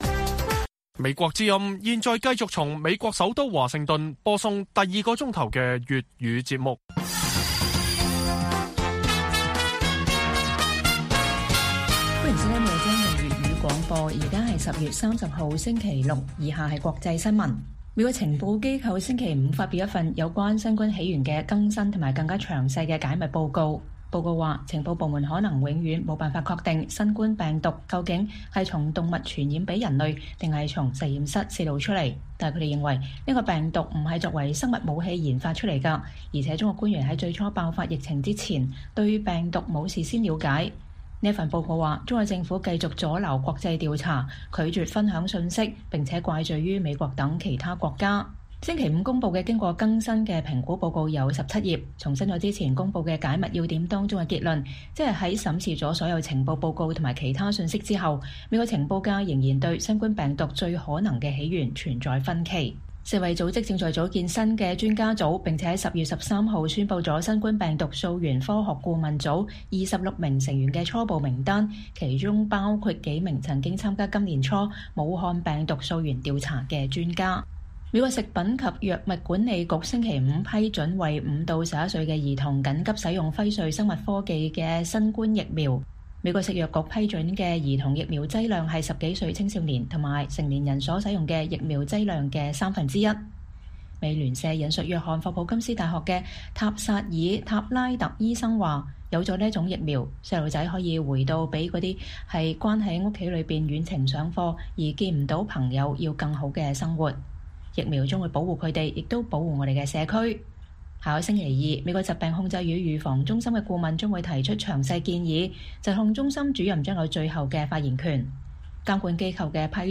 粵語新聞 晚上10-11點:美情報界發表更新報告：可能永遠無法確定新冠病毒起源